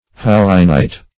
hauynite - definition of hauynite - synonyms, pronunciation, spelling from Free Dictionary